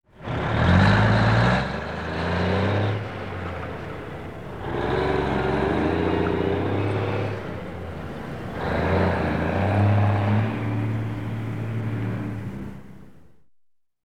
Звуки тягача
Грузовик дальнобойщика завелся и сам уехал